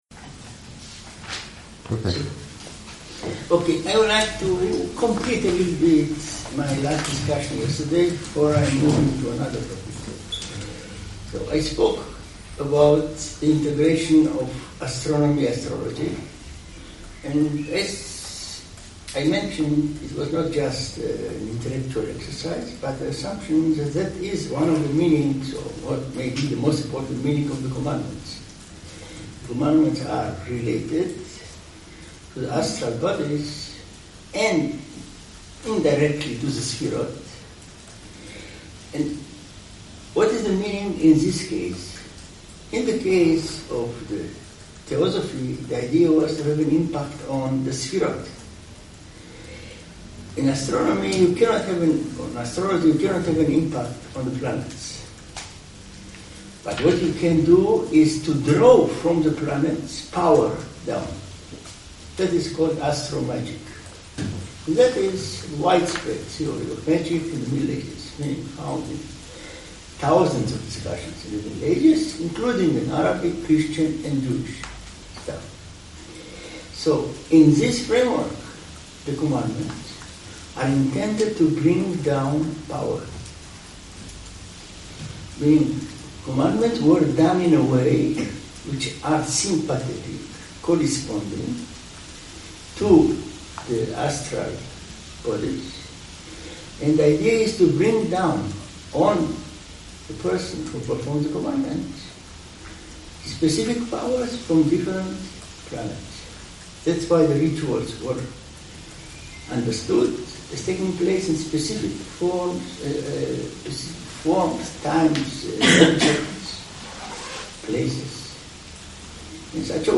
2010 Seminario